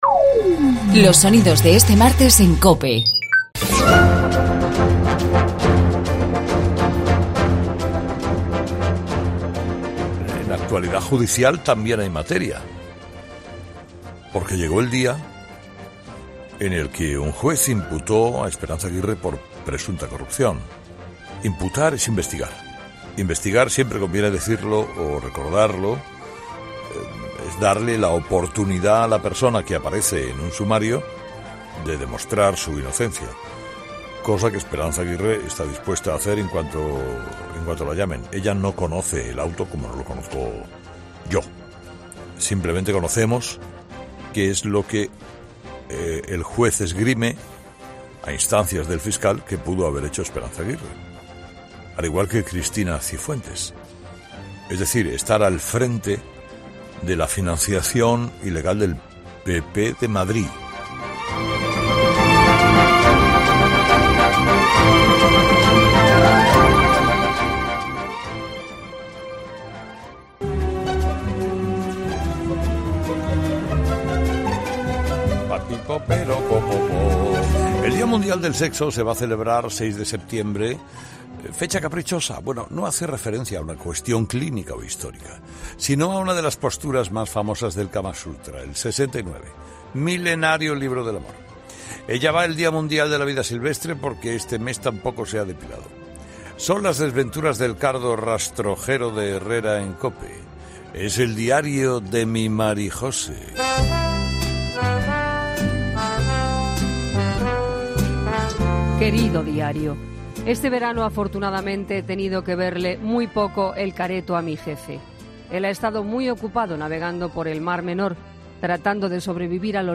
Repasa los mejores sonidos del día en COPE